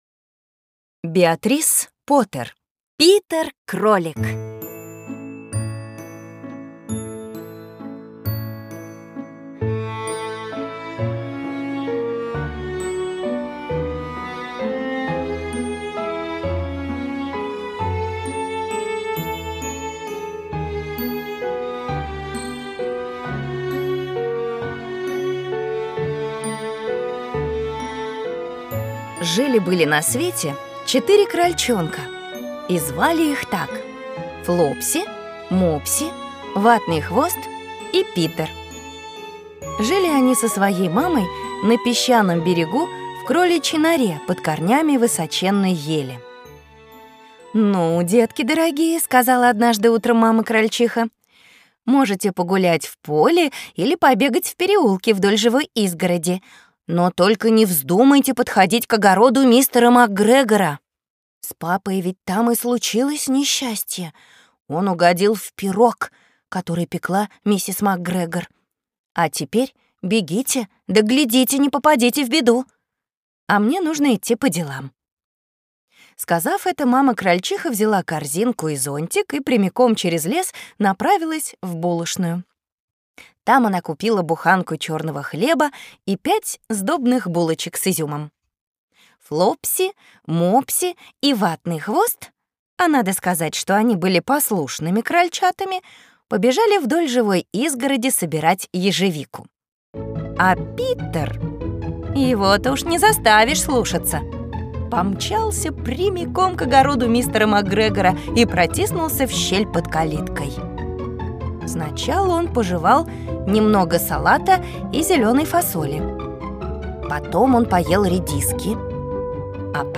Аудиокнига Кролик Питер и другие | Библиотека аудиокниг